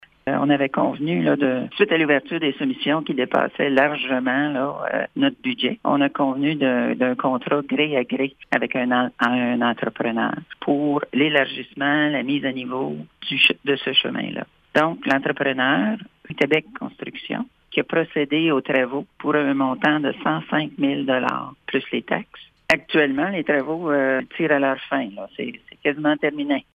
Cheryl Sage-Christensen est la mairesse de Lac-Sainte-Marie et elle nous explique comment elle et son conseil ont résolu de procéder :